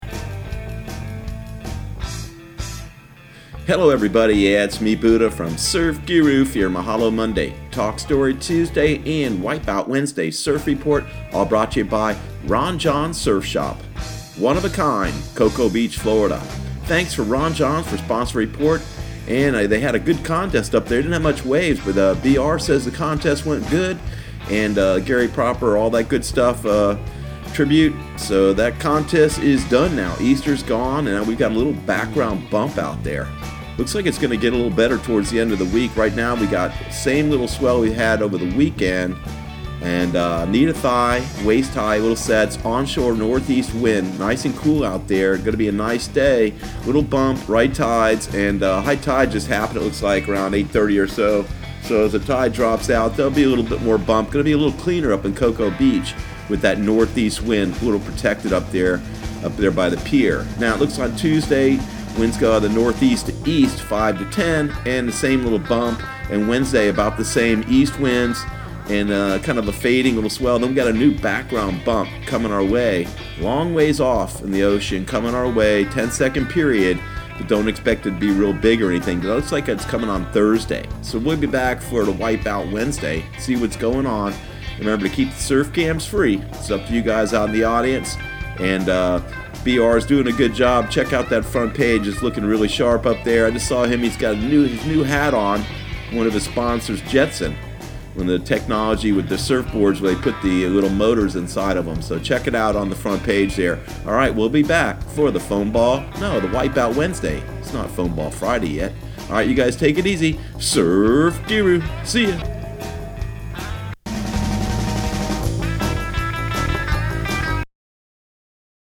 Surf Guru Surf Report and Forecast 04/22/2019 Audio surf report and surf forecast on April 22 for Central Florida and the Southeast.